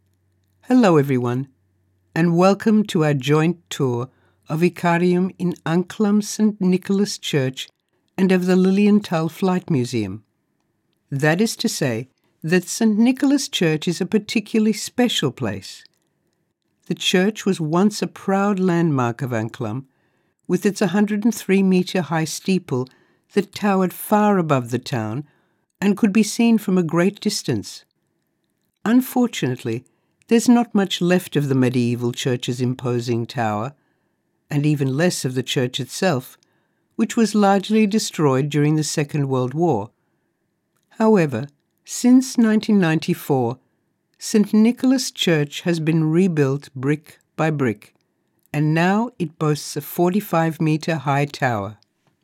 Female
English (Australian)
My voice overs are confident, warm, conversational, expressive, engaging, versatile and clear.
Tour Guide